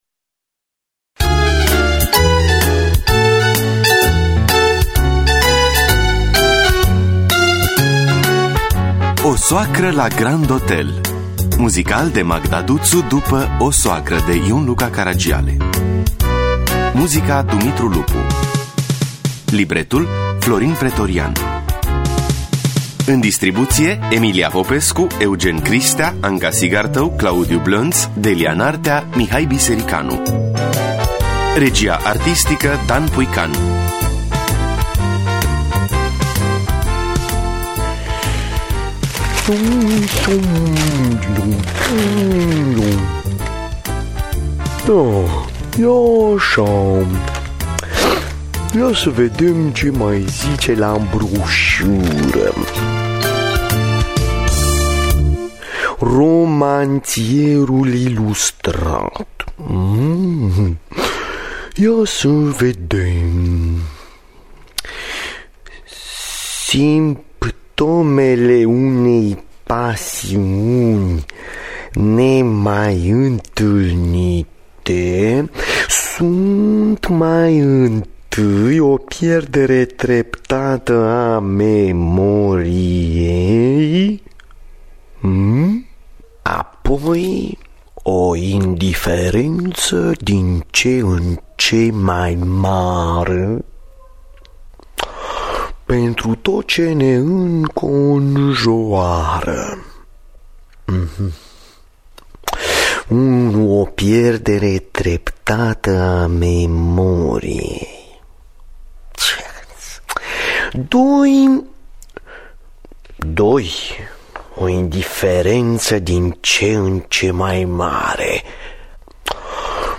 Muzical